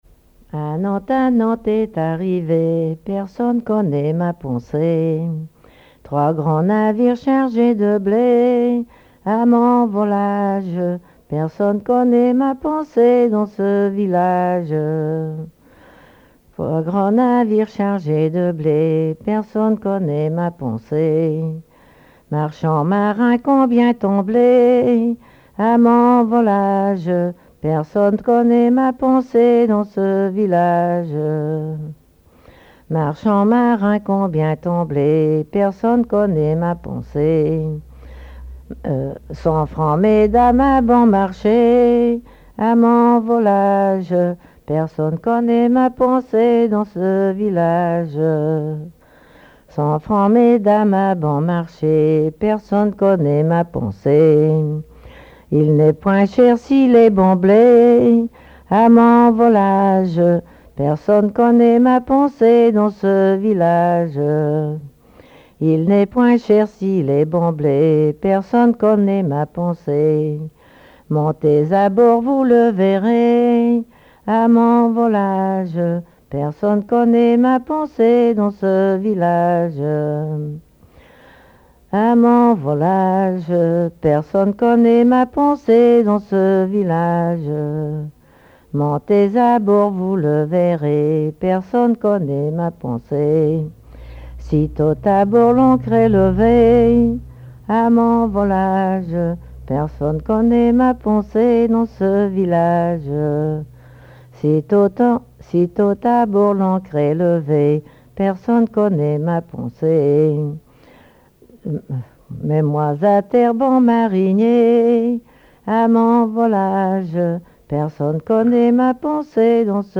gestuel : à marcher
Genre laisse
Pièce musicale inédite